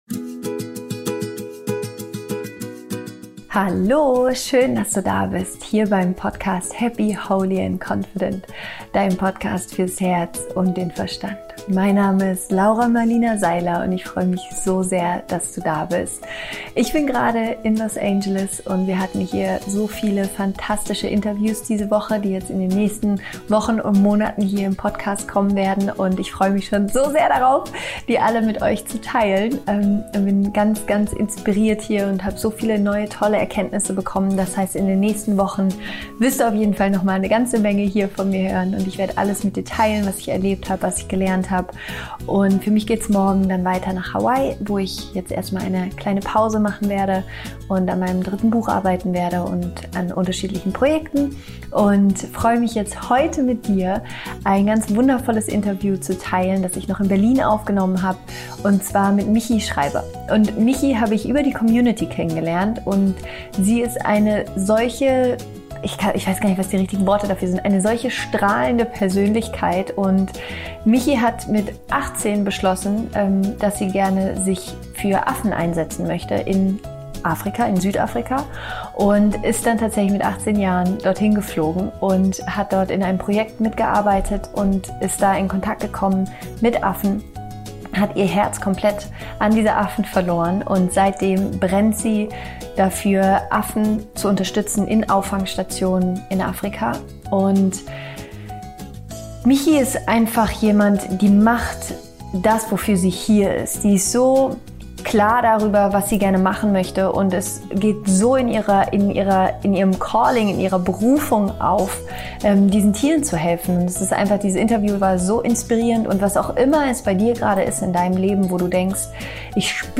Folge dem Ruf deines Herzens – Interview Special